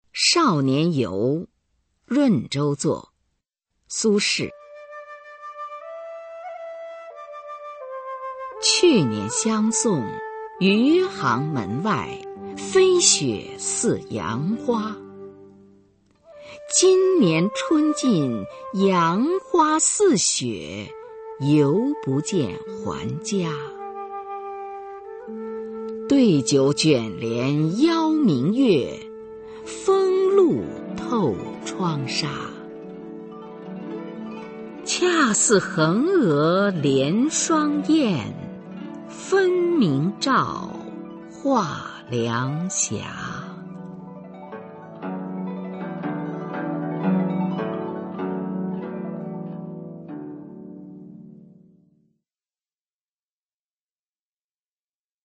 [宋代诗词诵读]苏轼-少年游·润州作 宋词朗诵